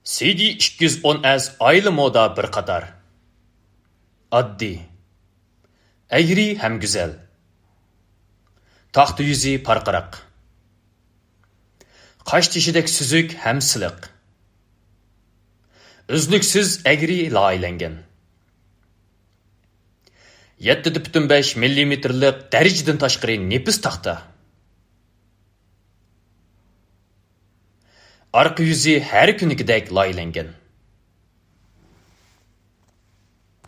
大气稳重